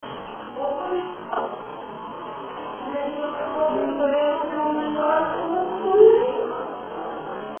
Cool EVP from IndyPara’s Elmendorph Inn Investigation
This EVP came up on my audio as well as my video recorder. It appears to be a young boy.